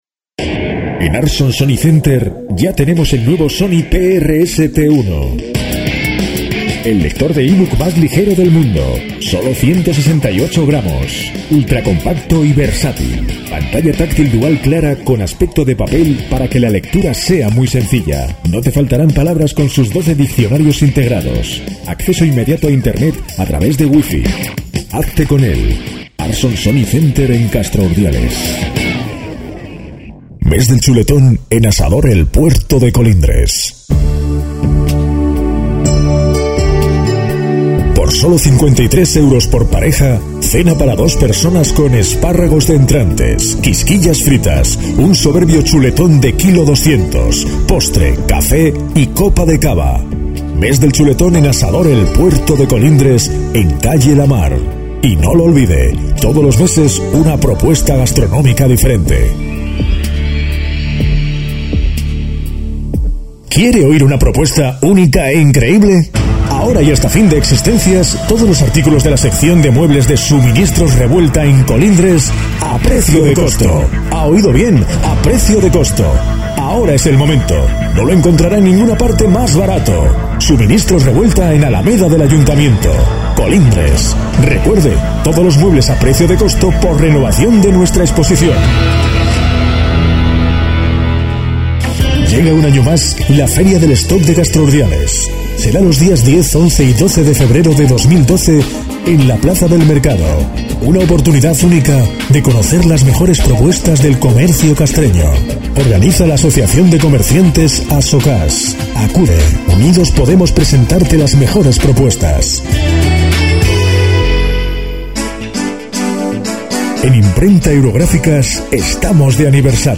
Reel audio publicitario